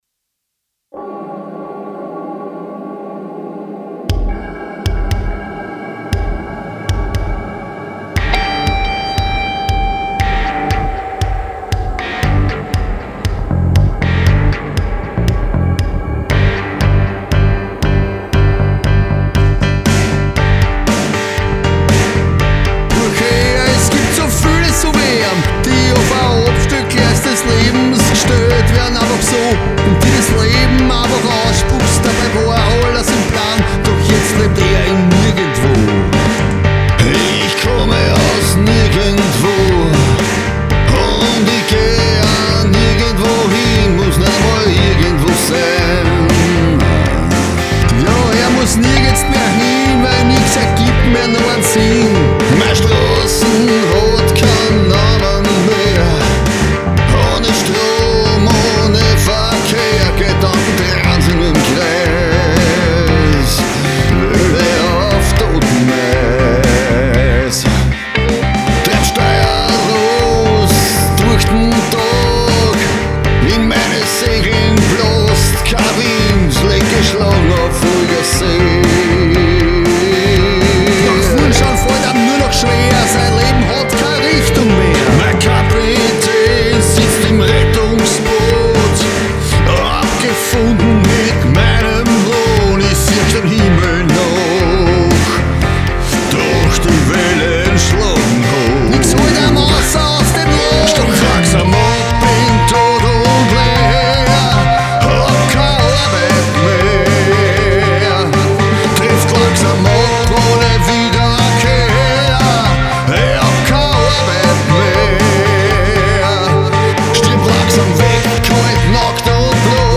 mit ERZÄHLER OKTOBER 2020